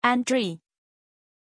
Pronunciación de Andrée
pronunciation-andrée-zh.mp3